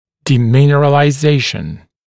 [dɪˌmɪnərəlaɪ’zeɪʃn][диˌминэрэлай’зэйшн]деминерализация (напр. зуба)